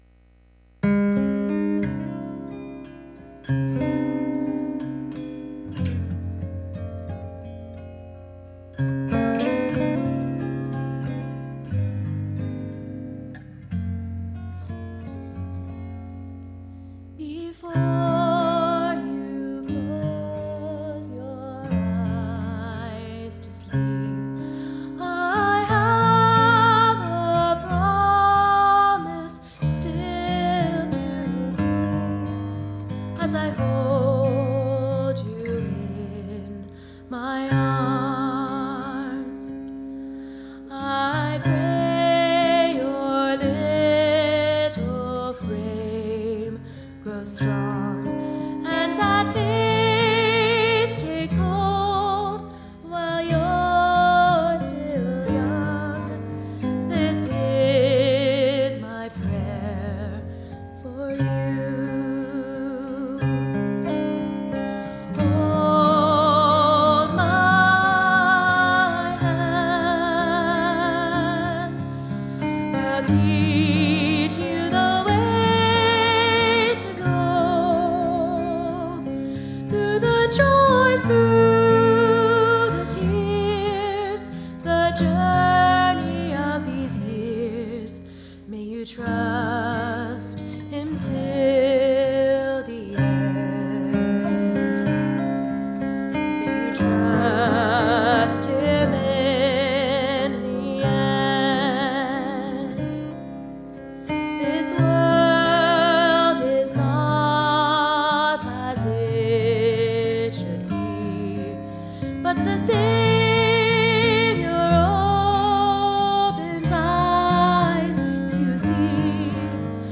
Sunday Morning Music
Solo